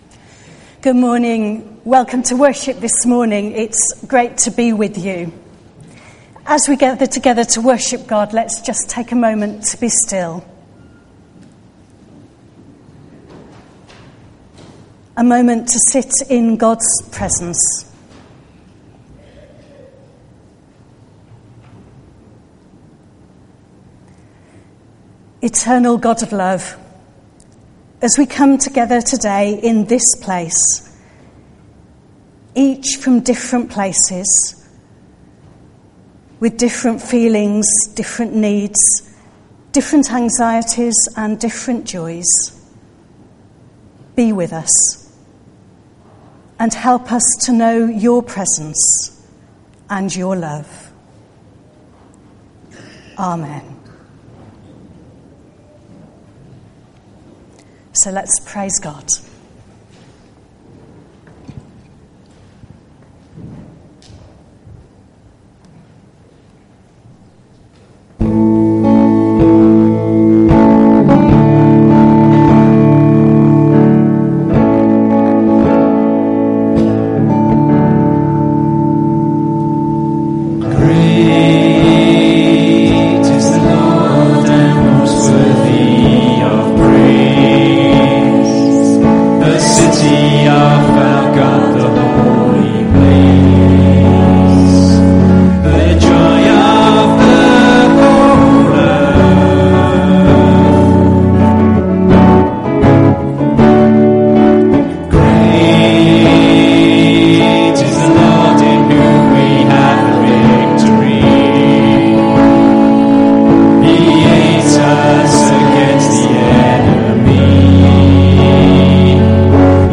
From Service: "10.30am Service"